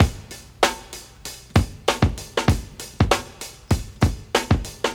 • 97 Bpm Drum Loop Sample D# Key.wav
Free breakbeat sample - kick tuned to the D# note. Loudest frequency: 1318Hz
97-bpm-drum-loop-sample-d-sharp-key-bVp.wav